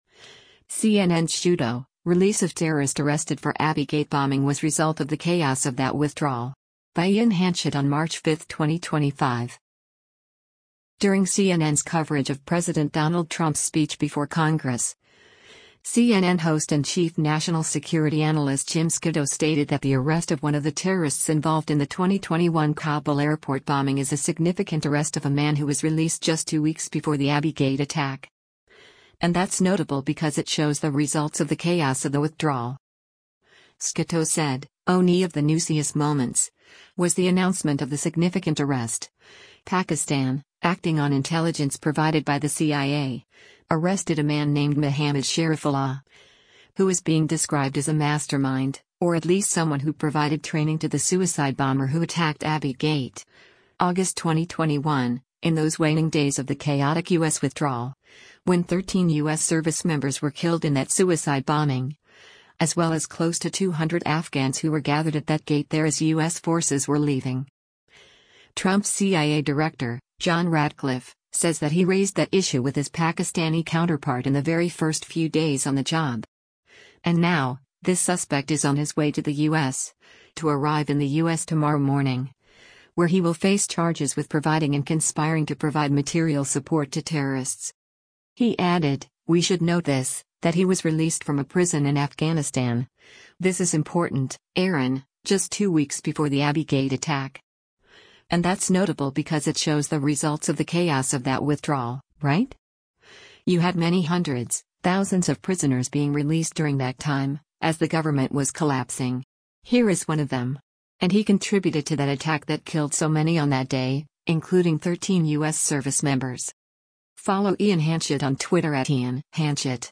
During CNN’s coverage of President Donald Trump’s speech before Congress, CNN host and Chief National Security Analyst Jim Scuitto stated that the arrest of one of the terrorists involved in the 2021 Kabul airport bombing is a “significant arrest” of a man who was released “just two weeks before the Abbey Gate attack.